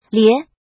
lié
lie2.mp3